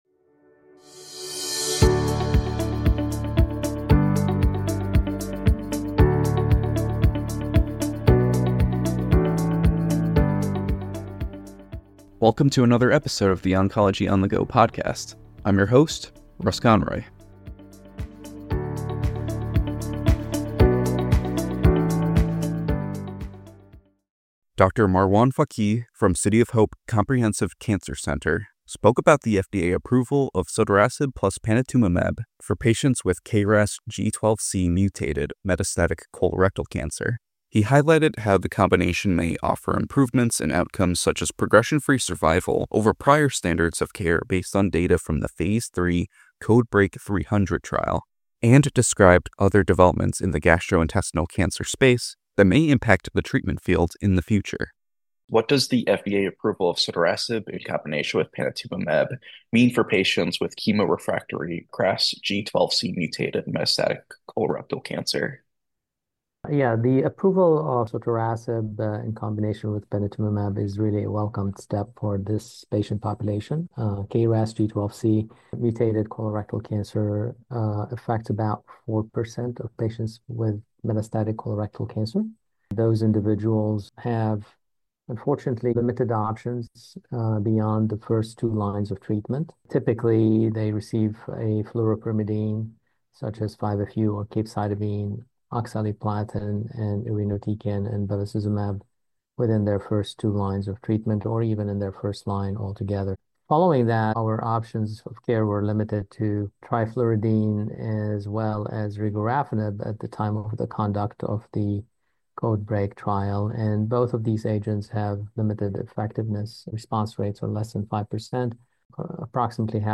In a conversation with CancerNetwork®